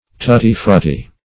What does tutti-frutti mean?